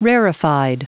Prononciation du mot rarefied en anglais (fichier audio)
Prononciation du mot : rarefied